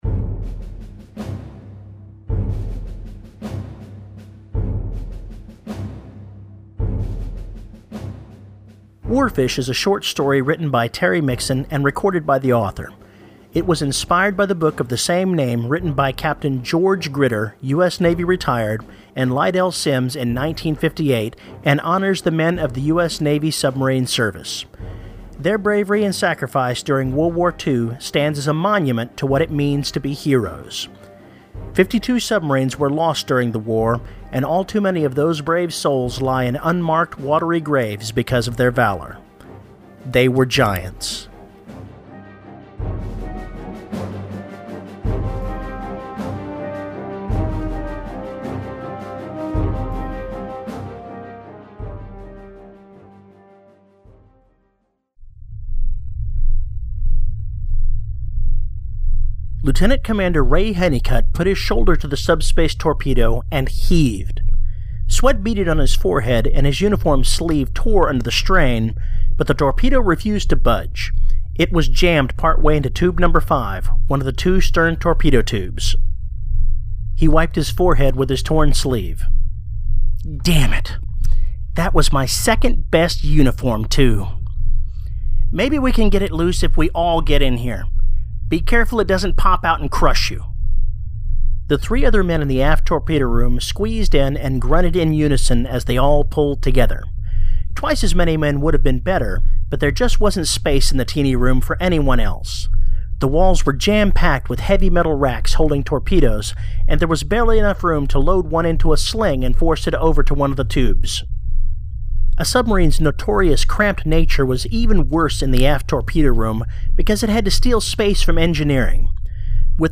Just a couple of nerdy Army veterans geeking out on things that go "abracadabra," "pew," "zoom," "boop-beep" and rhyme with Science Fiction & Fantasy.